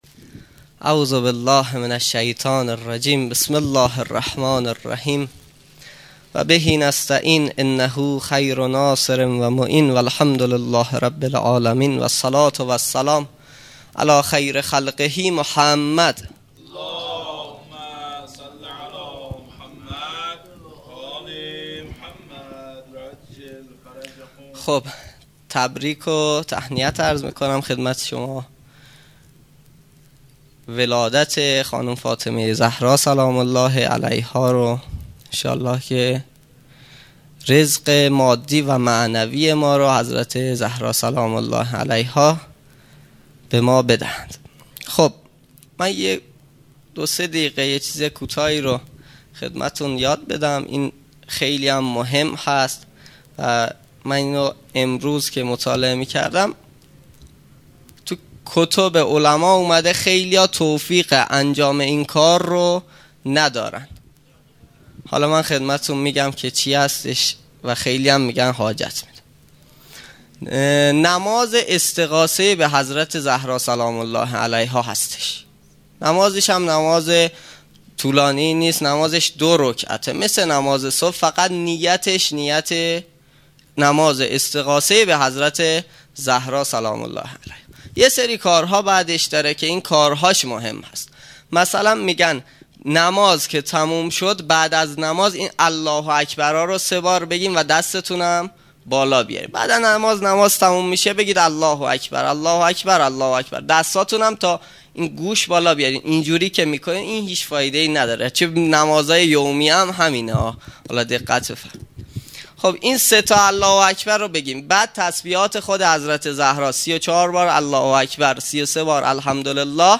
هیئت مکتب الزهرا(س)دارالعباده یزد - منبر احکام